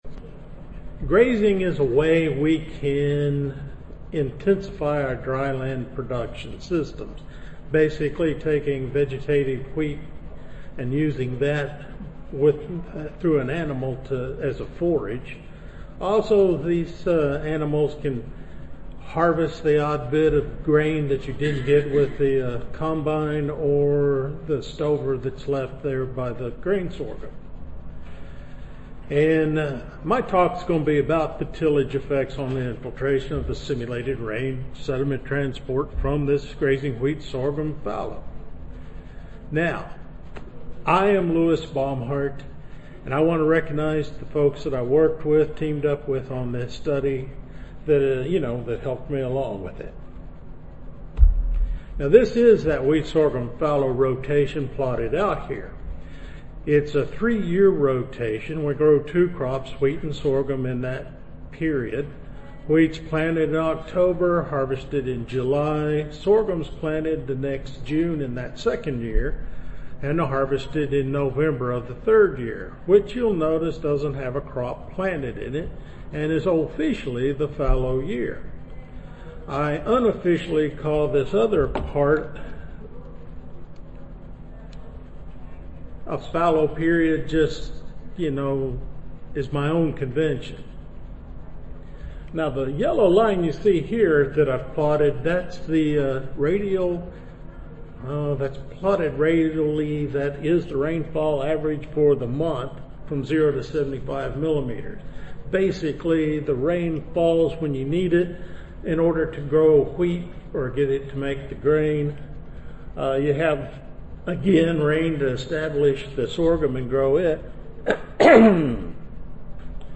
See more from this Division: ASA Section: Agronomic Production Systems See more from this Session: Semiarid Dryland Cropping Systems Oral